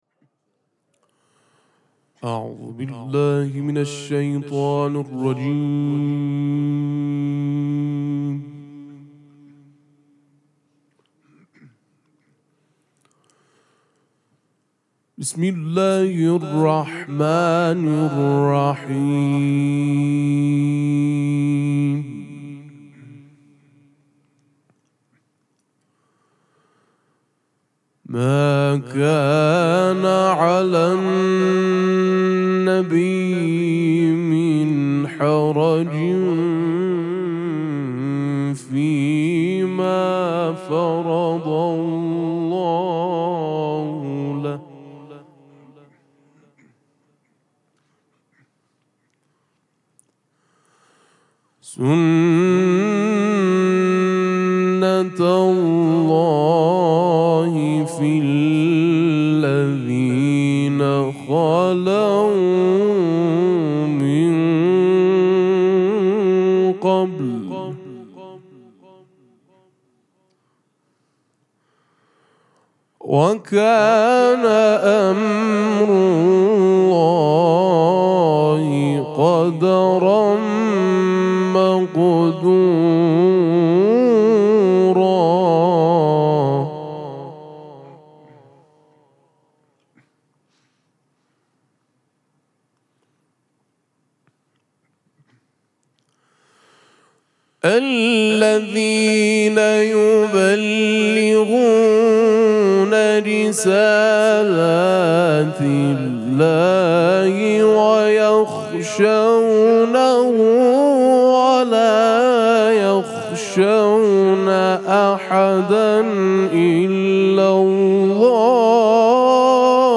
قرائت قرآن کریم
مراسم مناجات شب سوم ماه مبارک رمضان
سبک اثــر قرائت قرآن